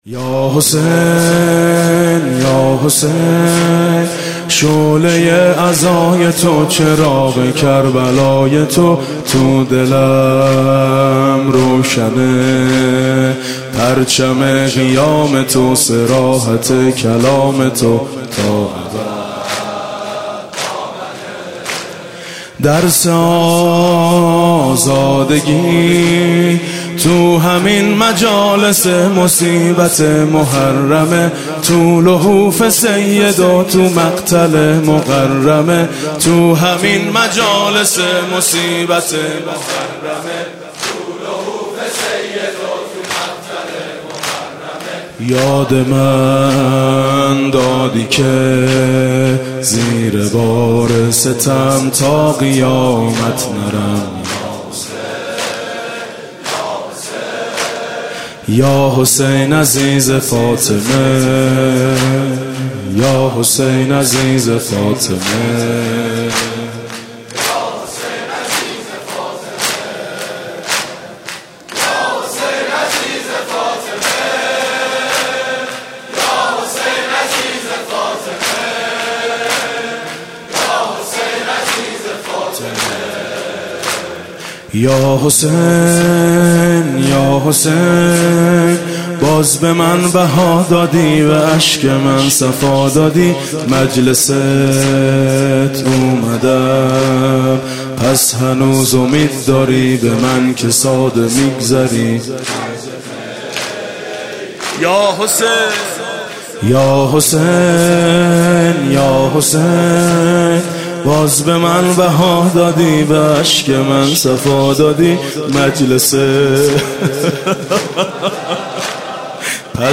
دم پایانی: یا حسین یا حسین، من کجا لیاقت اقامه عزای تو؟